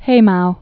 (hāmou)